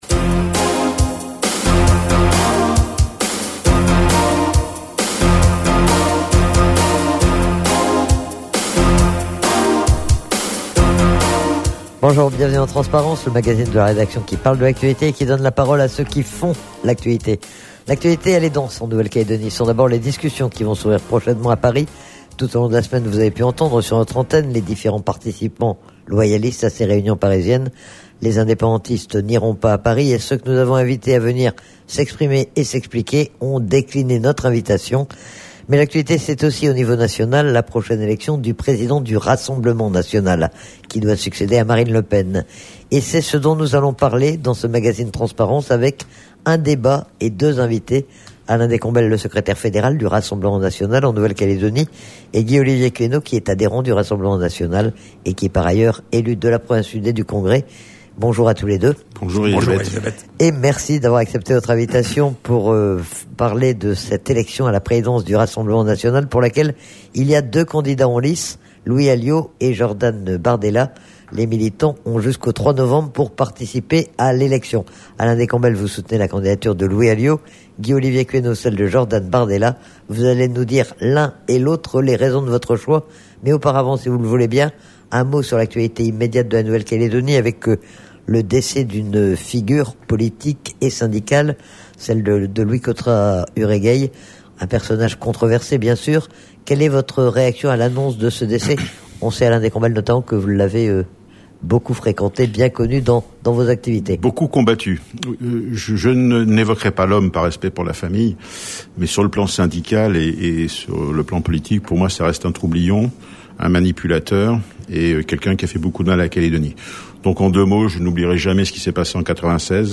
Menu La fréquence aux couleurs de la France En direct Accueil Podcasts TRANSPARENCE : VENDREDI 21/10/22 TRANSPARENCE : VENDREDI 21/10/22 20 octobre 2022 à 14:57 Écouter Télécharger Un magazine Transparence un peu particulier ce midi avec deux invités pour un débat.